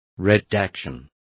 Complete with pronunciation of the translation of redactions.